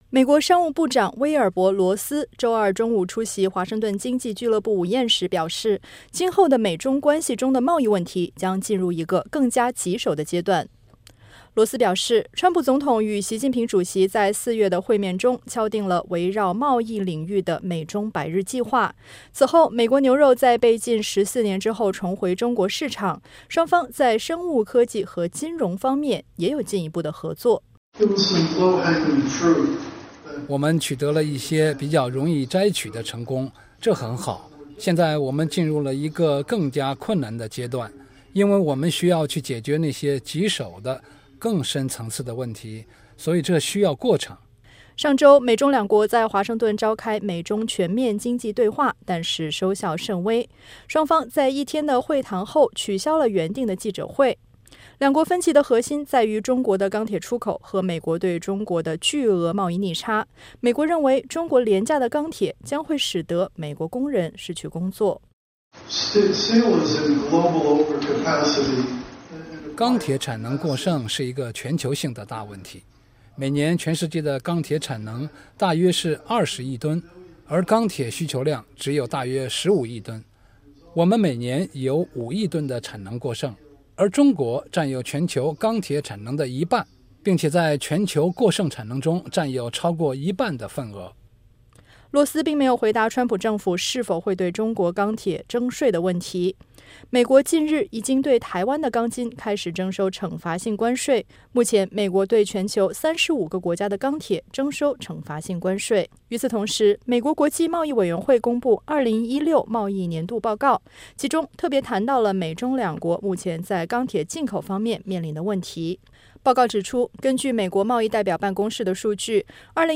商务部长威尔伯·罗斯周二中午出席华盛顿经济俱乐部午宴时表示，今后的美中关系中的贸易问题将进入一个更加棘手的阶段。